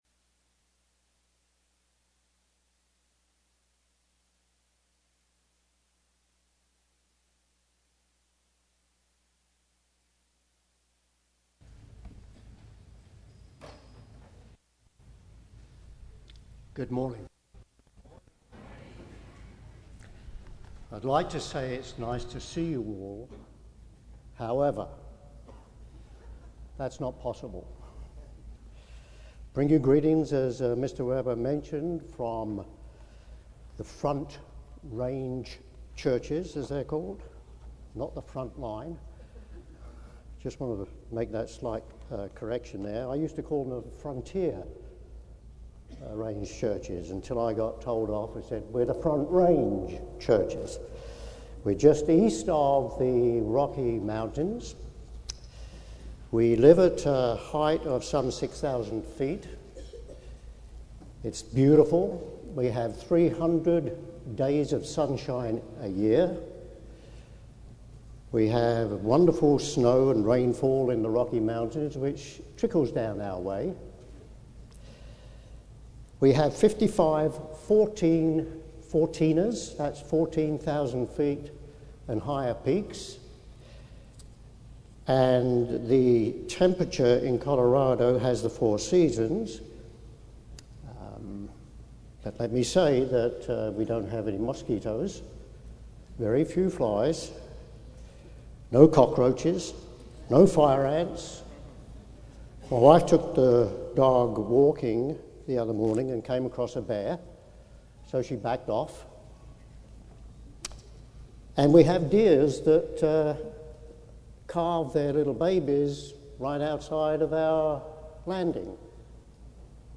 This sermon was given at the Oceanside, California 2014 Feast site.